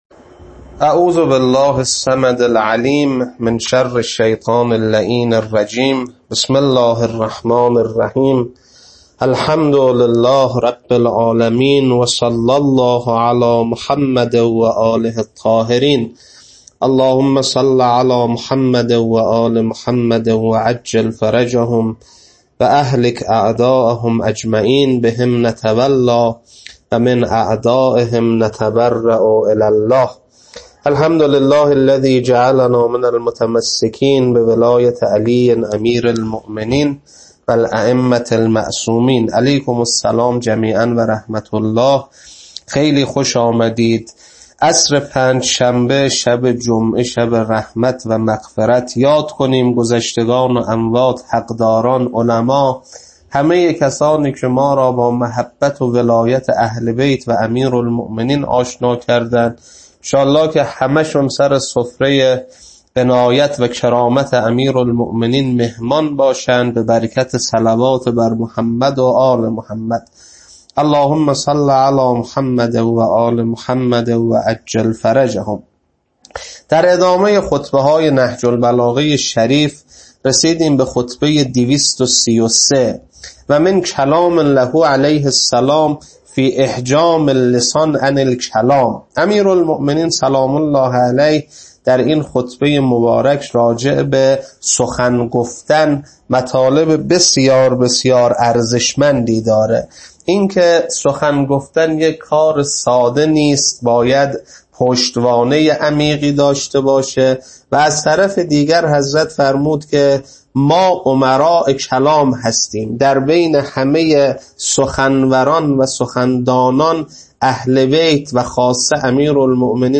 خطبه 233.mp3
خطبه-233.mp3